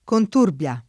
Conturbia [ kont 2 rb L a ] top. (Piem.)